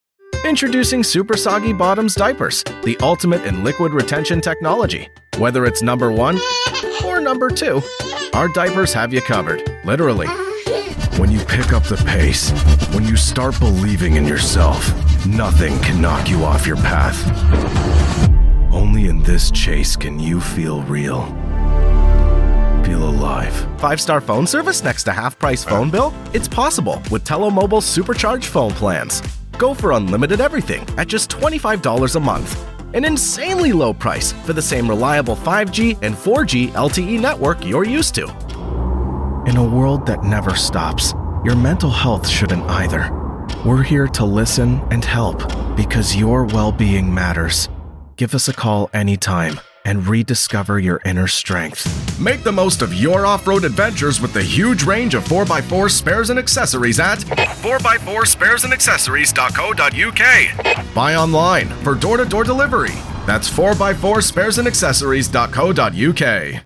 Anglais (canadien)
De la conversation
Décontractée
Amical